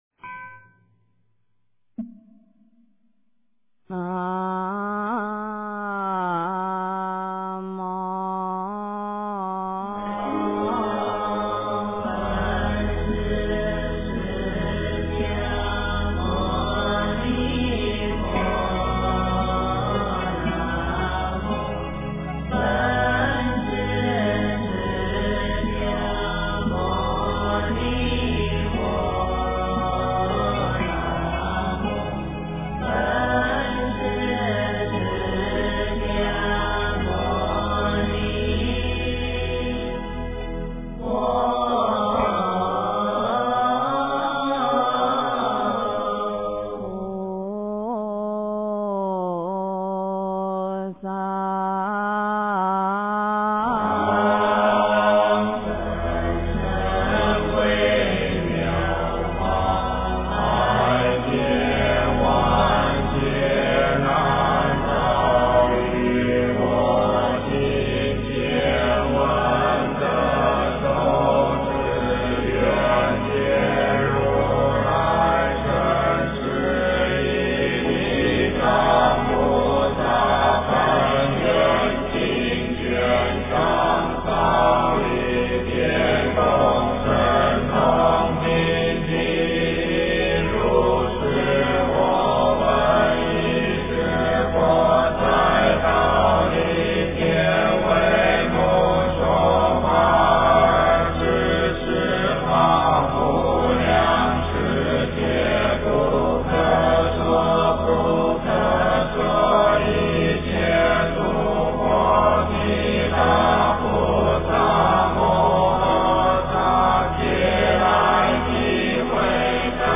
地藏经卷上 - 诵经 - 云佛论坛